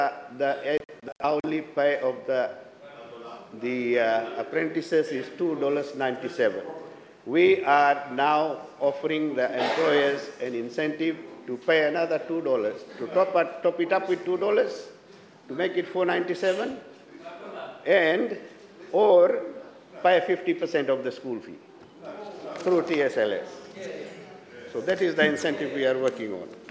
Speaking during the parliamentary session today, Minister Agni Deo Singh highlighted the government’s intentions to address the labor shortage by implementing incentives for programs such as apprenticeship schemes.